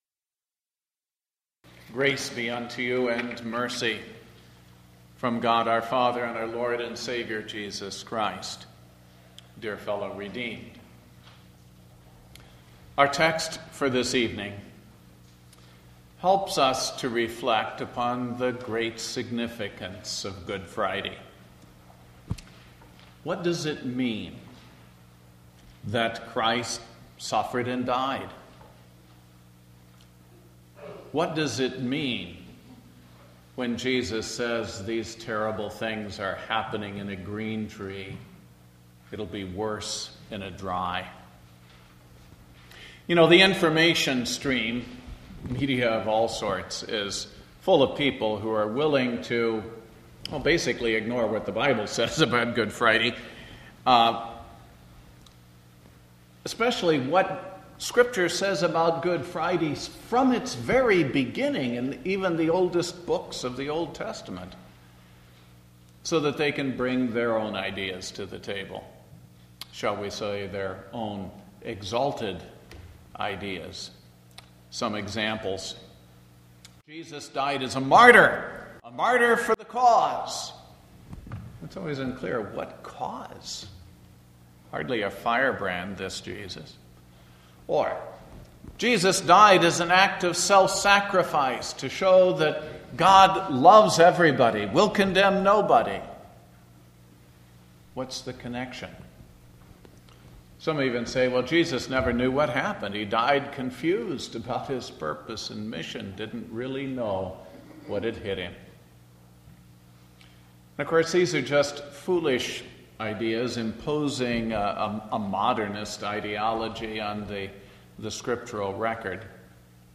Good Friday